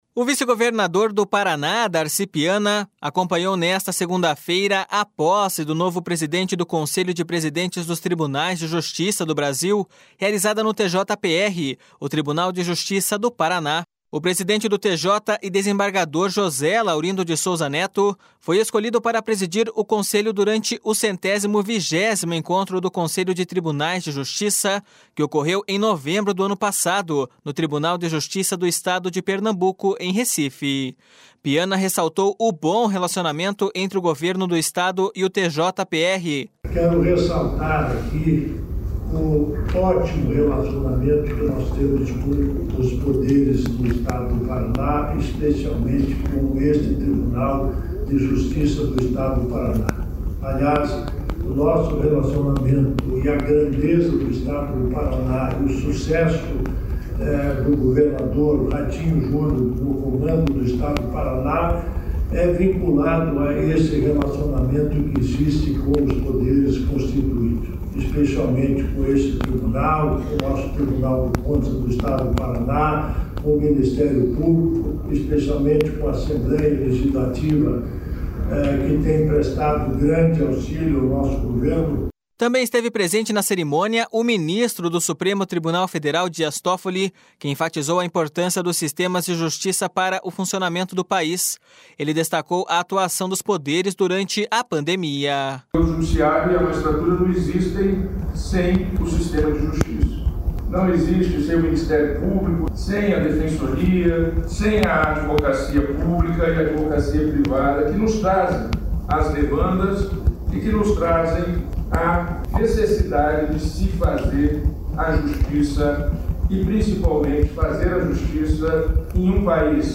O vice-governador do Paraná, Darci Piana, acompanhou nesta segunda-feira a posse do novo presidente do Conselho de Presidentes dos Tribunais de Justiça do Brasil, realizada no TJPR, o Tribunal de Justiça do Paraná.
//SONORA DARCI PIANA//
//SONORA DIAS TOFFOLI//
O presidente empossado, desembargador José Laurindo de Souza Netto, ressaltou a necessidade de autonomia e fortalecimento dos Tribunais de Justiça. //SONORA JOSÉ LAURINDO DE SOUZA NETTO//